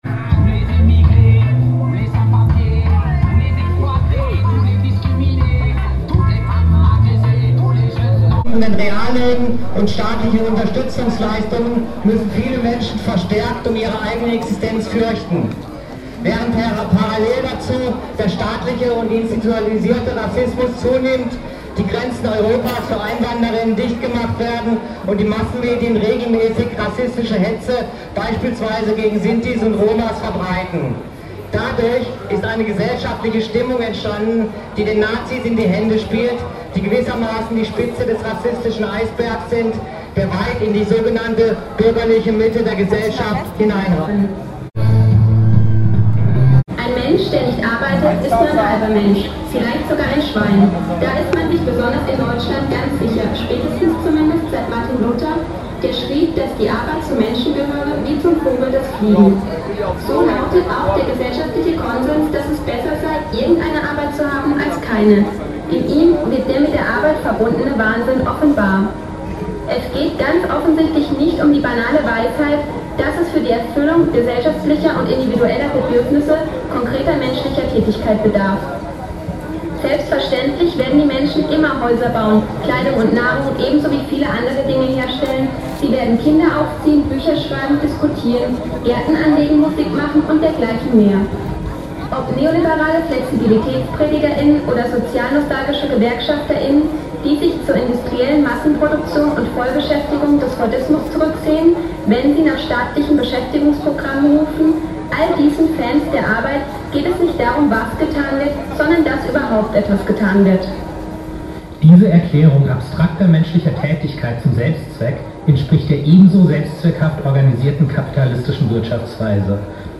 Neben der DGB-Demonstration fand in Freiburg am 1.Mai auch eine libertäre Demo statt. Eine Collage lässt sie uns nochmal miterleben.